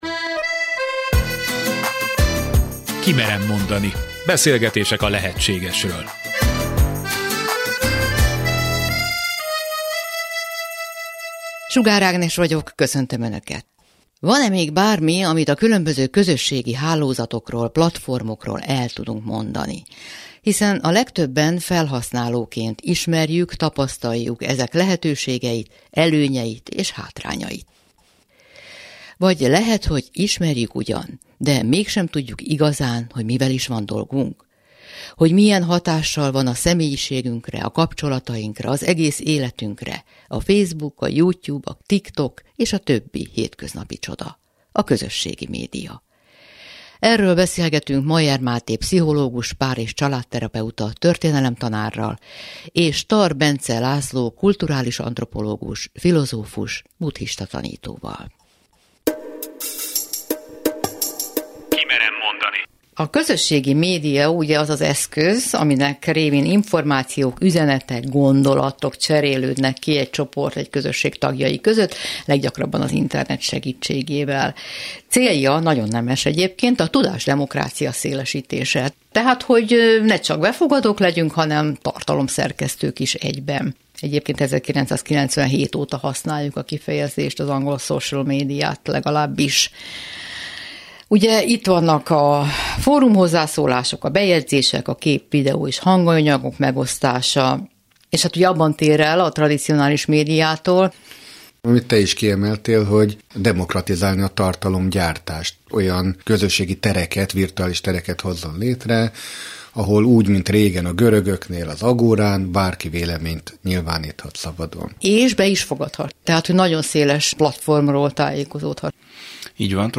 Ebben az részben erről beszélgettünk